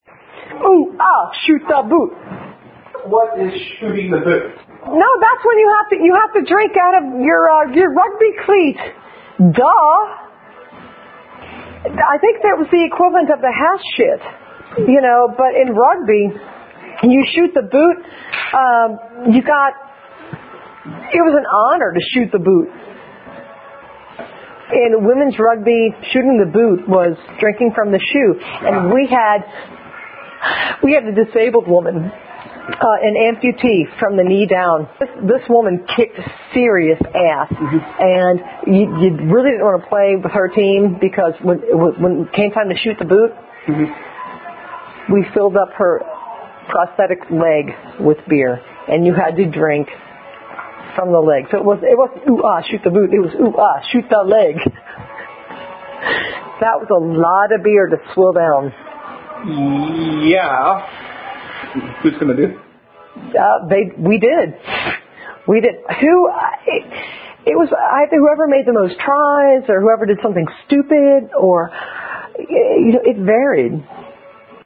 Shoot The Boot [Ditty - With Explanation Of Context And Use].mp3